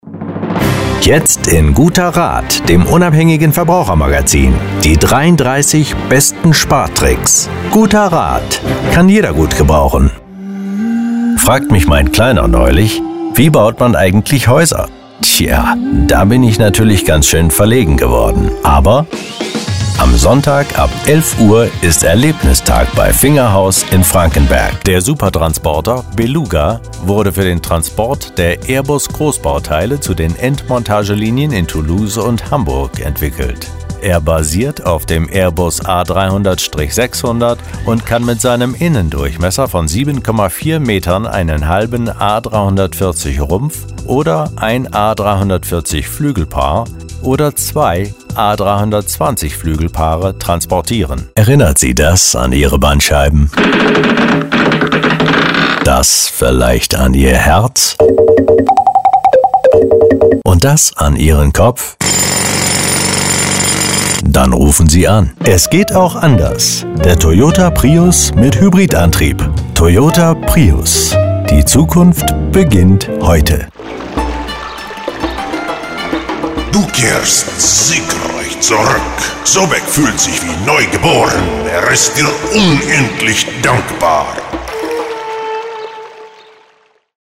Stimme: Weich, warm, werblich - authoritĂ€r. Die sonore Stimme eines ErzĂ€hlers
deutsch
Kein Dialekt
Sprechprobe: Werbung (Muttersprache):